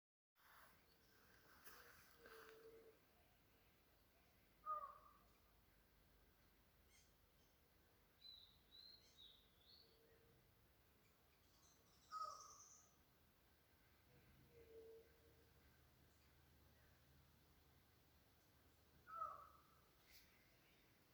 Collared Forest Falcon (Micrastur semitorquatus)
Life Stage: Adult
Detailed location: Reserva Chopí sa´yju (eby)
Condition: Wild
Certainty: Recorded vocal